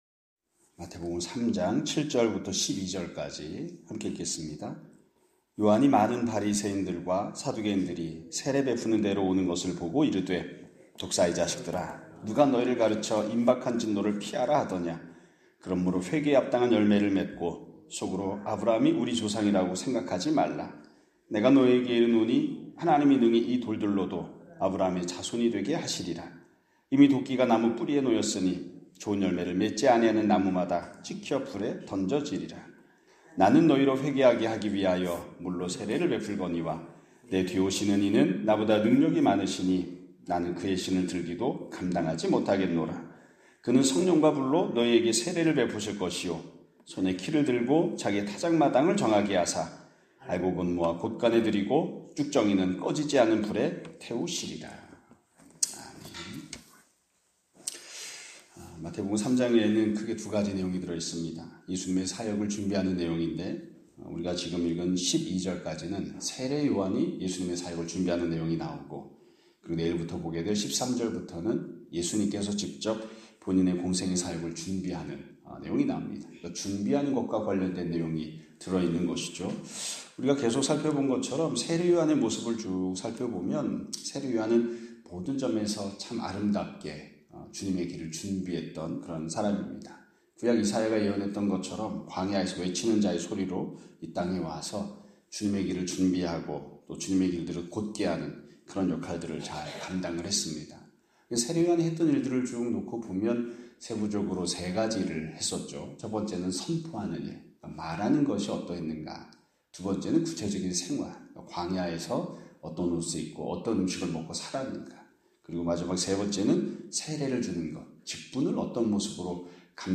2025년 4월 16일(수요일) <아침예배> 설교입니다.